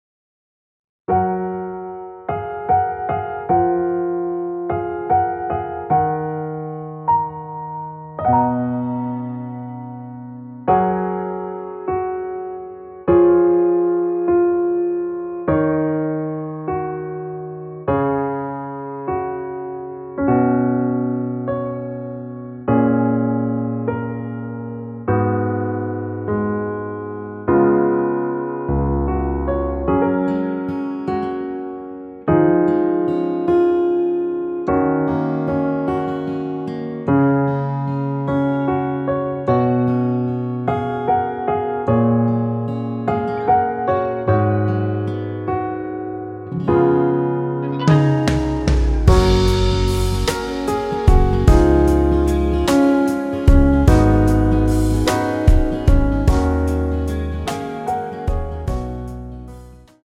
원키에서(+1)올린 MR입니다.
F#
앞부분30초, 뒷부분30초씩 편집해서 올려 드리고 있습니다.
중간에 음이 끈어지고 다시 나오는 이유는